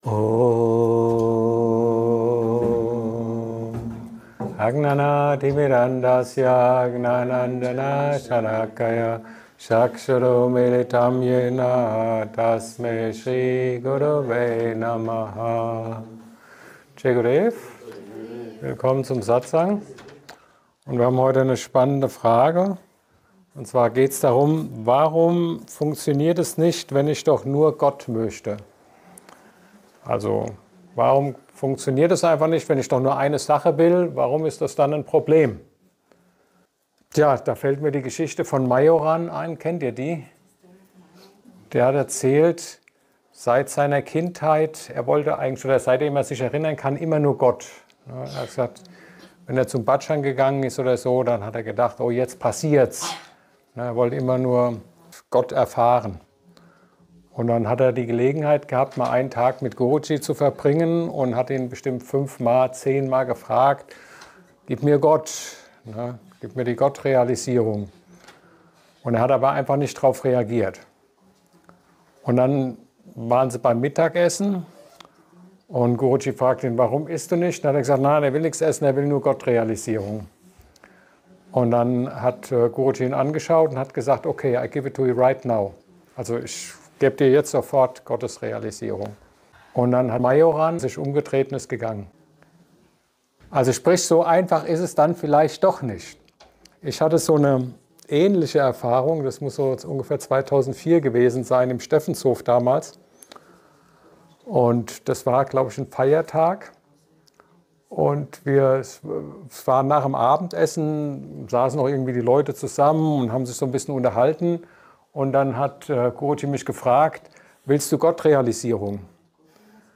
Ein Satsang